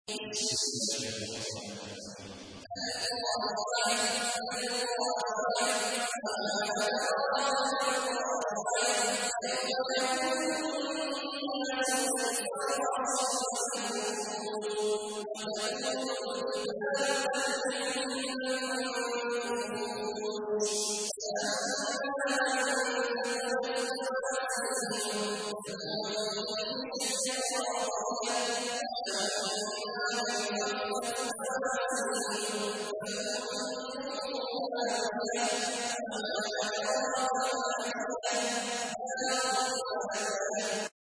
تحميل : 101. سورة القارعة / القارئ عبد الله عواد الجهني / القرآن الكريم / موقع يا حسين